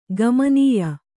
♪ gamanīya